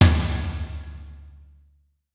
Percusión 2: timbal 1
membranófono
timbal
batería
electrónico
golpe
sintetizador